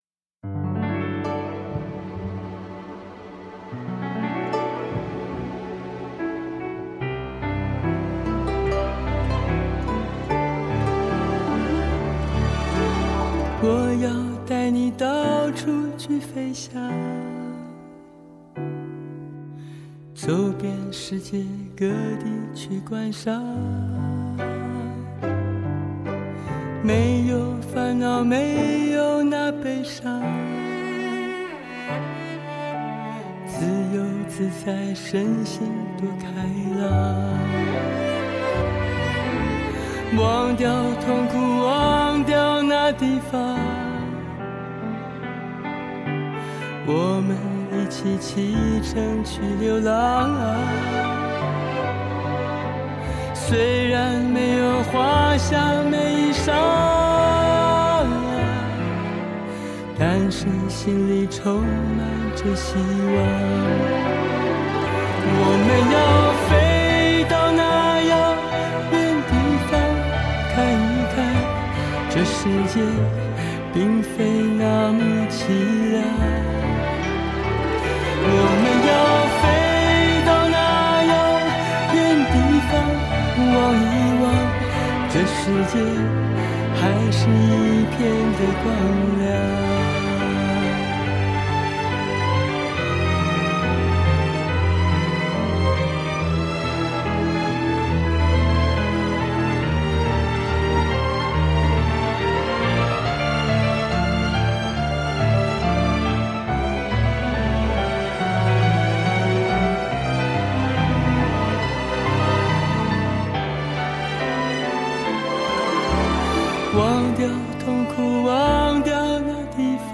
重新编唱经典情歌 岁月洗练的绝赞嗓音